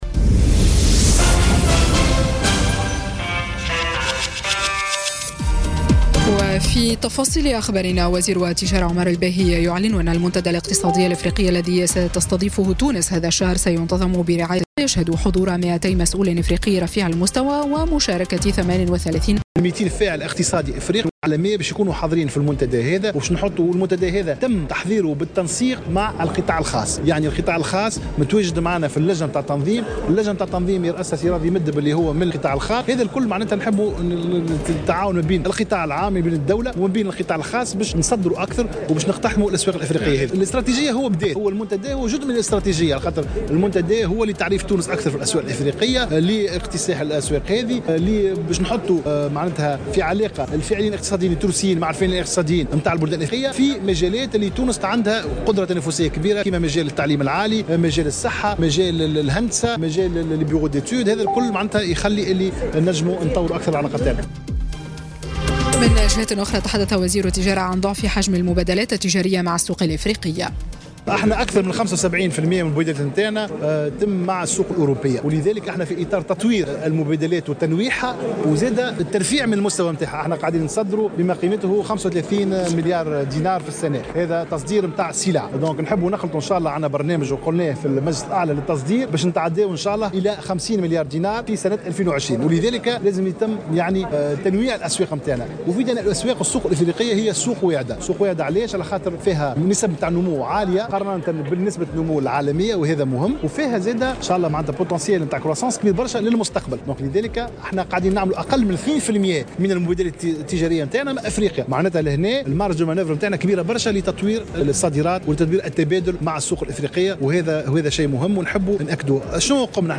نشرة أخبار منتصف النهار ليوم الثلاثاء 10 أفريل 2018